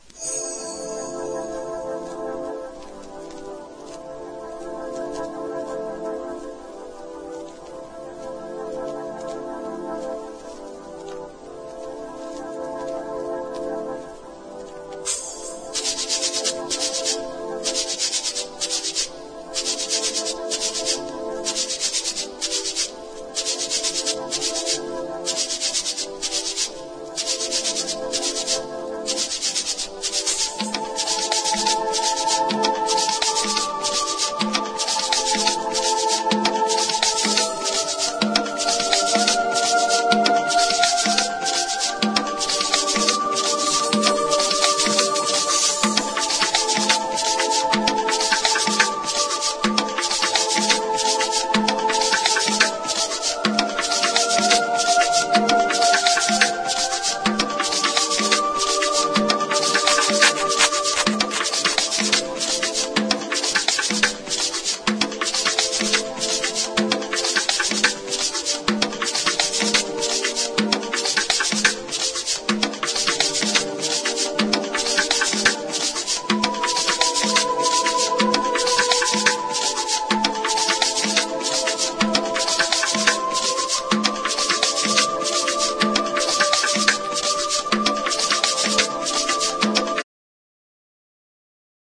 DEEP HOUSE / EARLY HOUSE